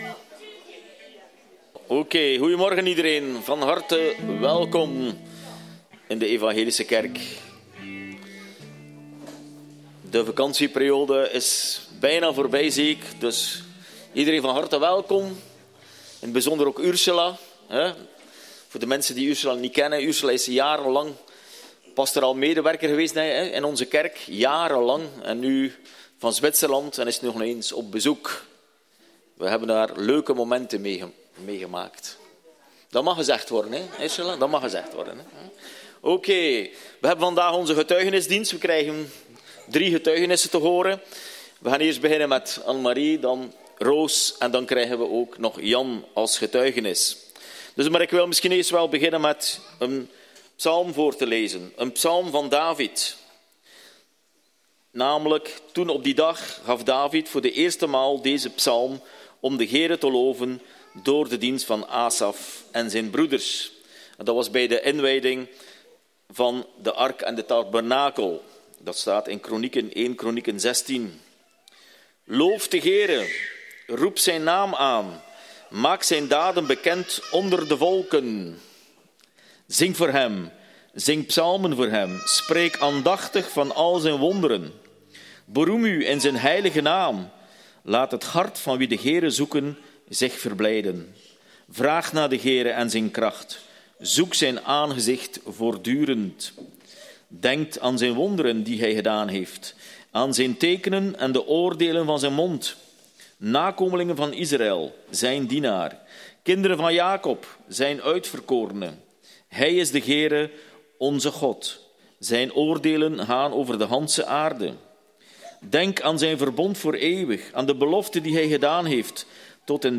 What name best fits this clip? GETUIGENISSENDIENST Dienstsoort: Getuigenissendienst